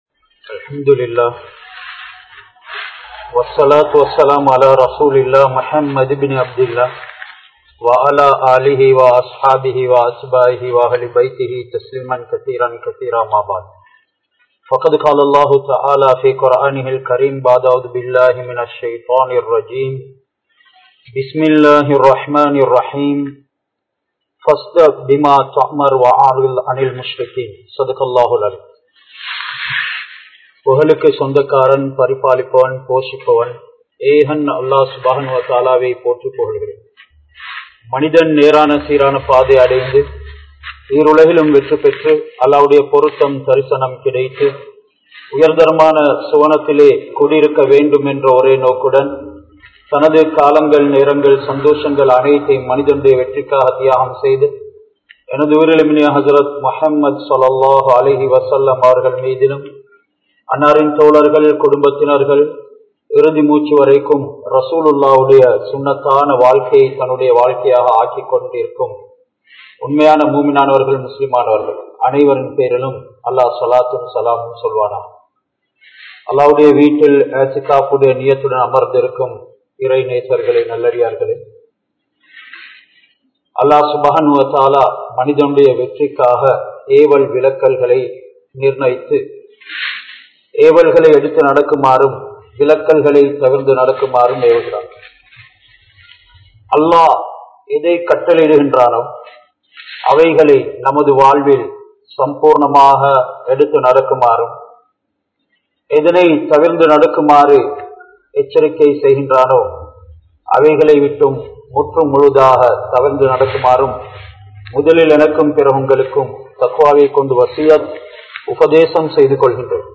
Nabi(SAW)Avarhalin Mun Maathiriyai Marantha Samooham (நபி(ஸல்)அவர்களின் முன்மாதிரியை மறந்த சமூகம்) | Audio Bayans | All Ceylon Muslim Youth Community | Addalaichenai
DanGolla Jumua Masjith